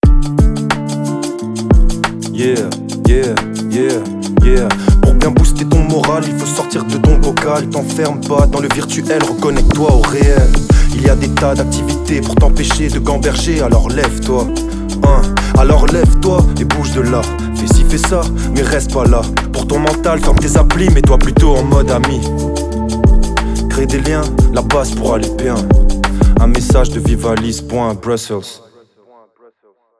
Une campagne par et pour les jeunes avec le slam